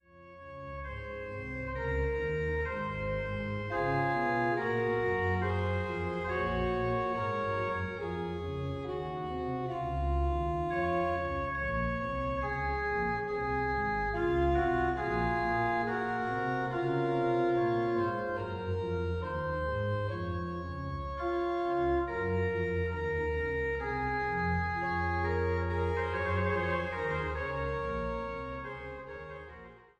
König-Orgel der Basilika des Klosters Steinfeld